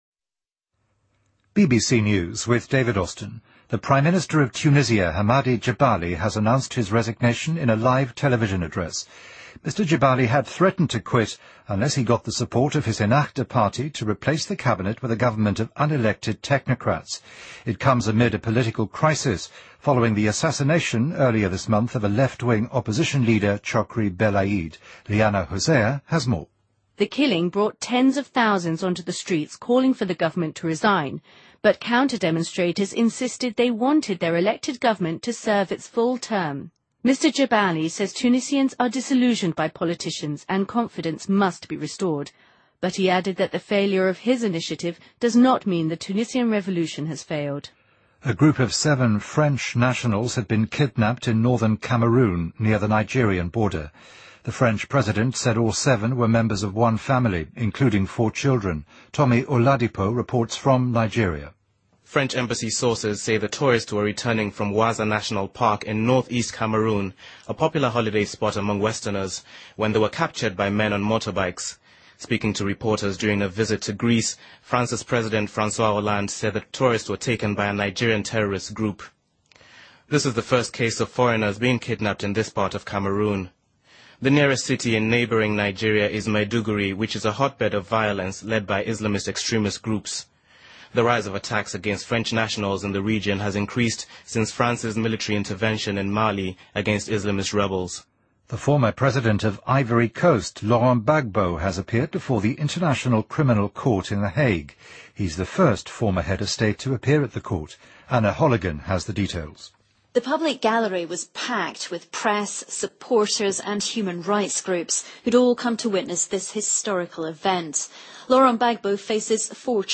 BBC news,突尼斯总理杰巴里在电视直播讲话中宣布辞职